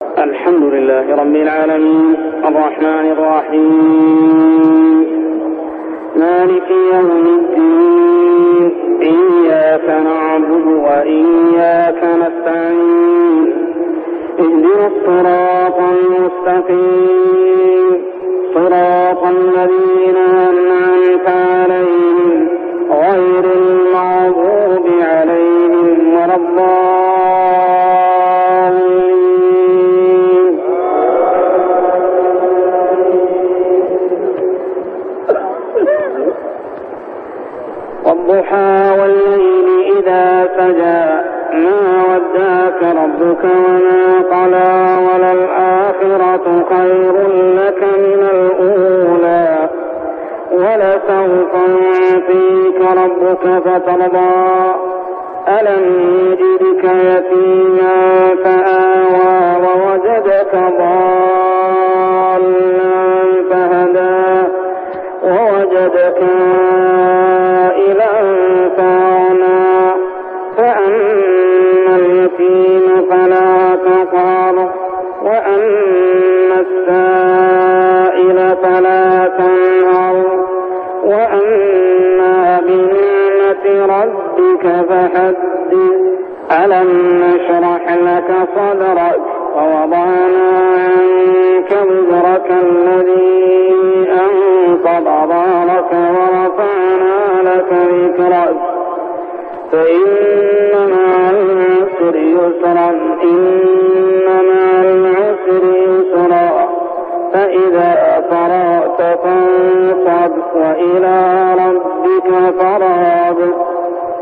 تلاوة من صلاة العشاء سورتي الضحى و الشرح كاملة عام 1399هـ | Isha prayer Surah Ad-duha and Ash-Sharh > 1399 🕋 > الفروض - تلاوات الحرمين